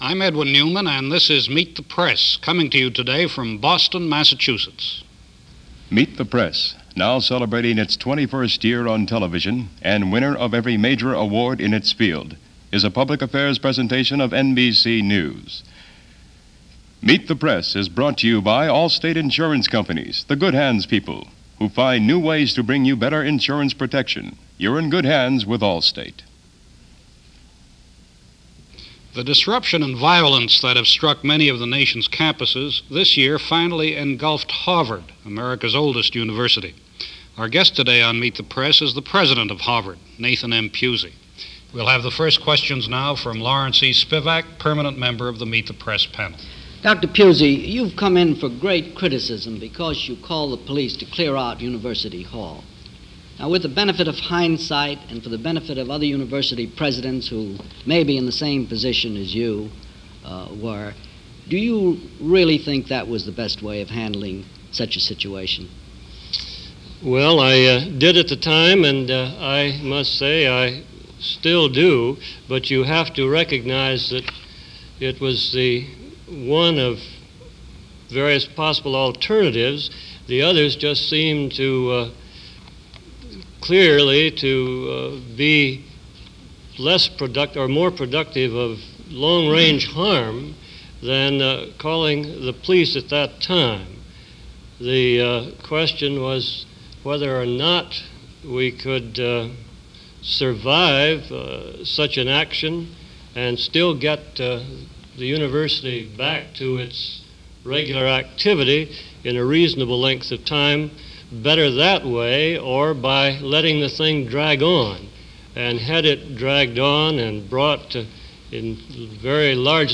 1969 - Harvard On Strike - A College President Faces The Press - Past Daily Reference Room